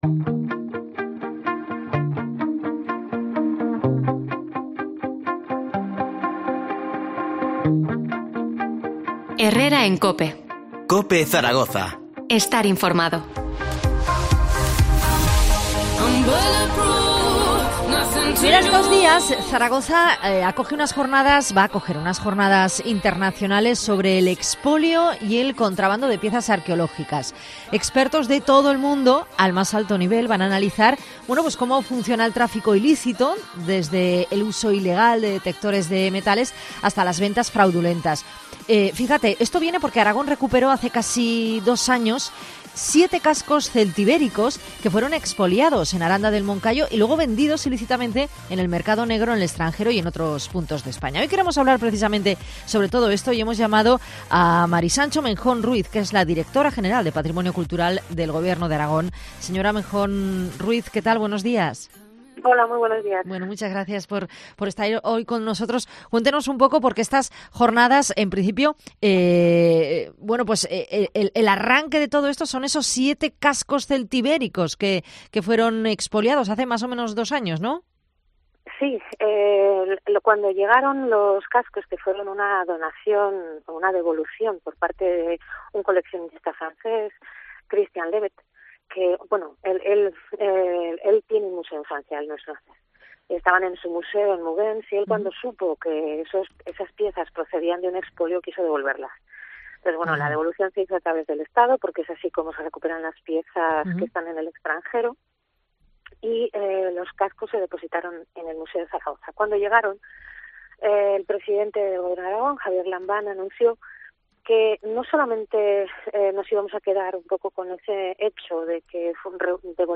Entrevista a Mª Sancho Menjón, directora general de Patrimonio de la DGA.